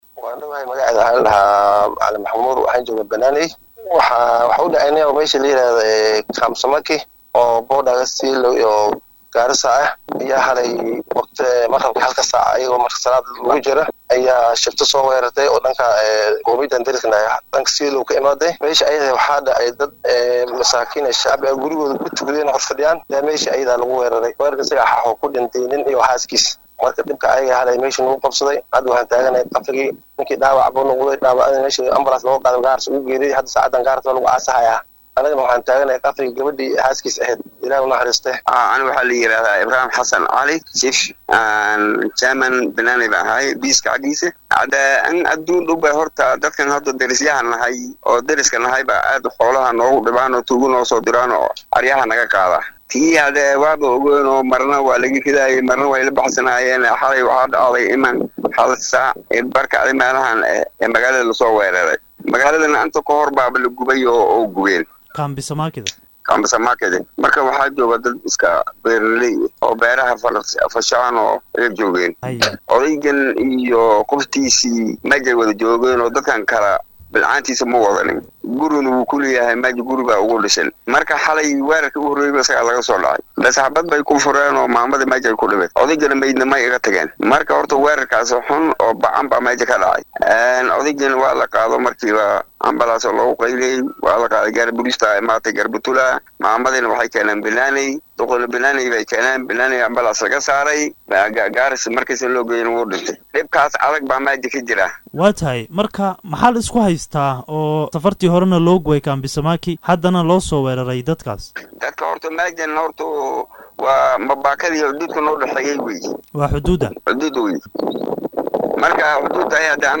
Qaar ka mid ah odayaasha iyo mas’uuliyiinta magaalada Banaaneey ayaa la hadlay idaacadda Star Fm.